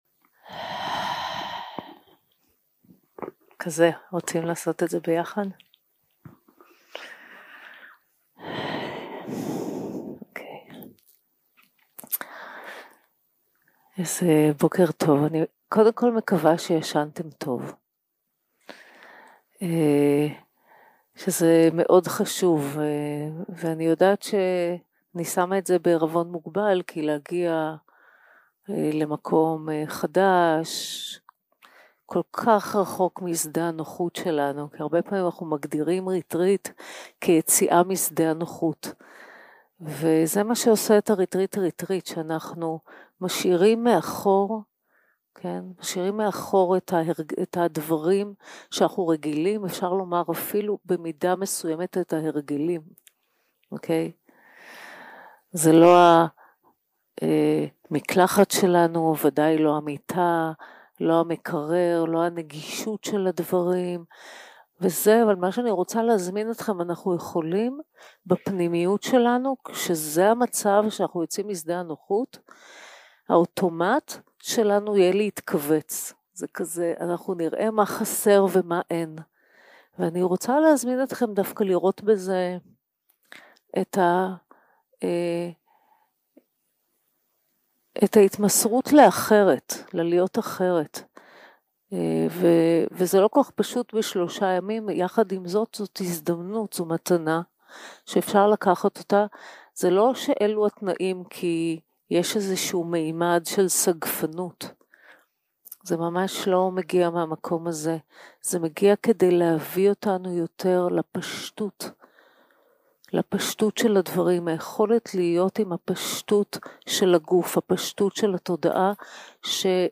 יום 2 - הקלטה 2 - בוקר - הנחיות למדיטציה - תשומת לב לנשימה והתרווחות בגוף 0:00 0:00 סוג ההקלטה: Dharma type: Guided meditation שפת ההקלטה: Dharma talk language: Hebrew